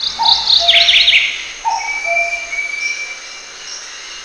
se-bird.wav